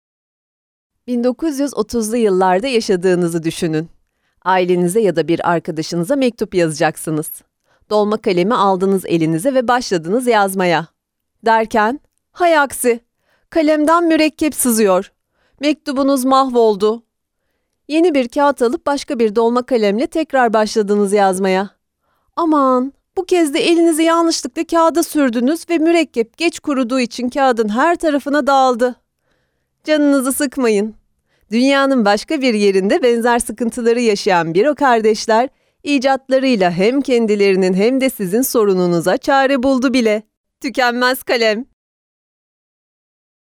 激情活力